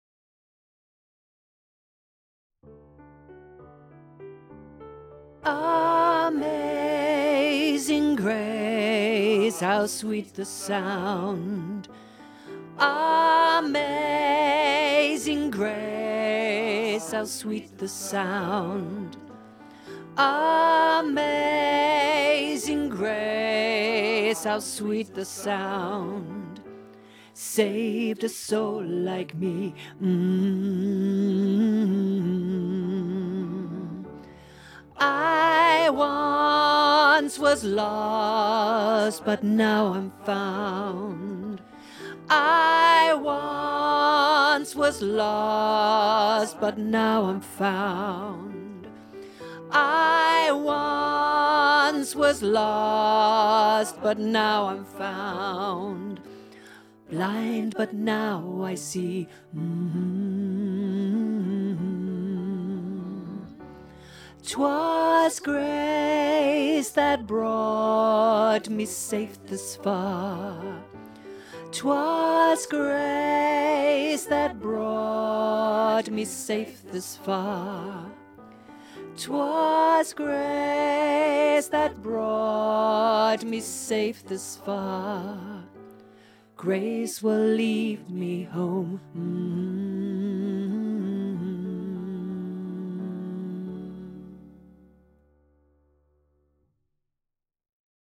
24 Amazing grace (Tenor learning track)
Genre: Choral.